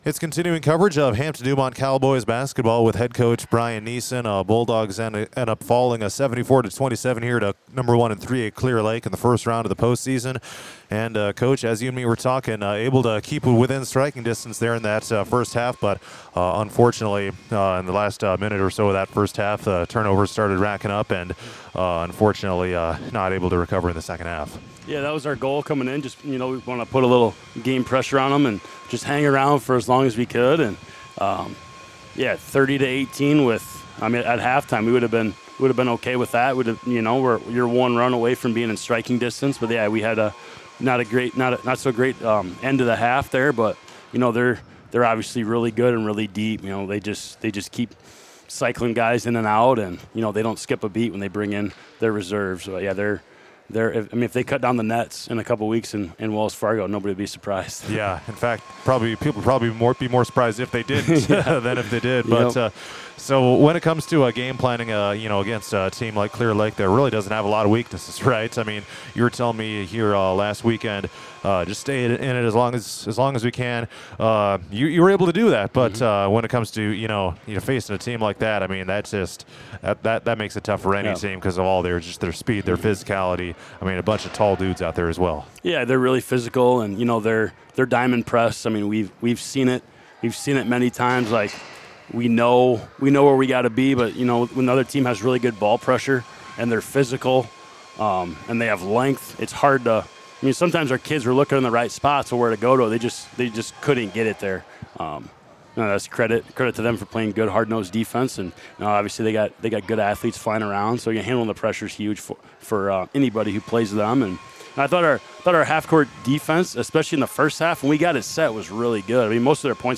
interview: